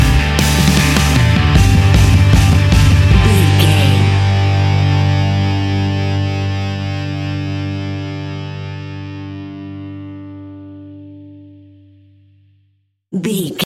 Epic / Action
Fast paced
Ionian/Major
hard rock
distortion
punk metal
rock instrumentals
Rock Bass
heavy drums
distorted guitars
hammond organ